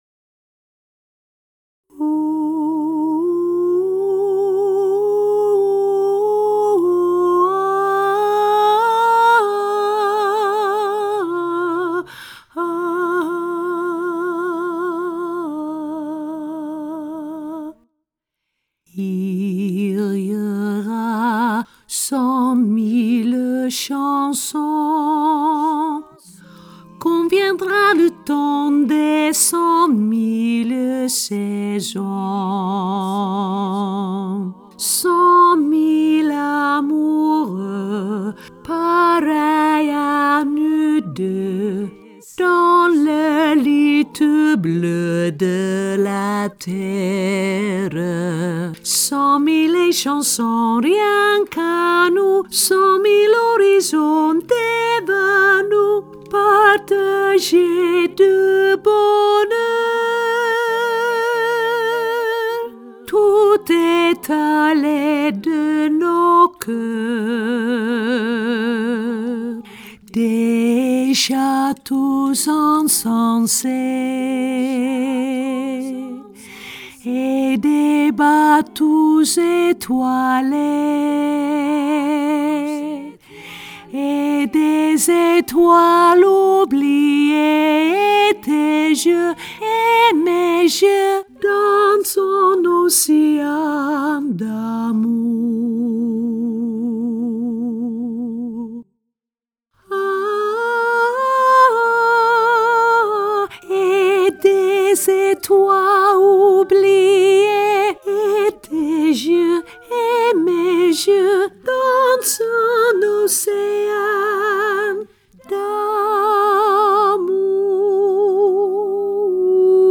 alt hoog